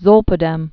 (zōlpə-dĕm)